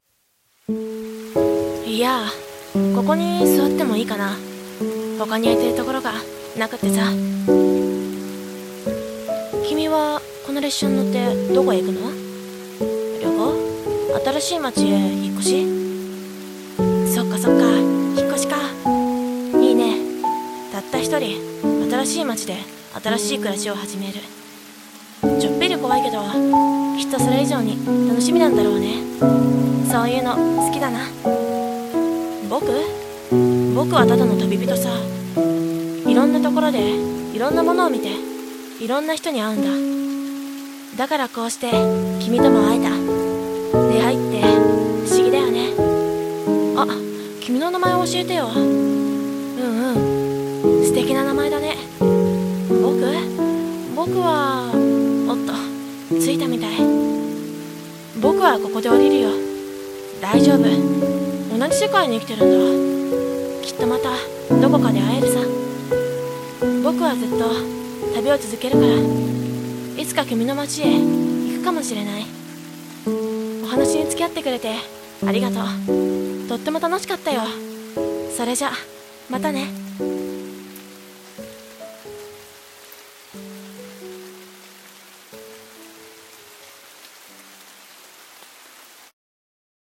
声劇「みしらぬネコ